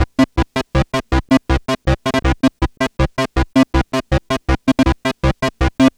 Track 16 - Arp 02.wav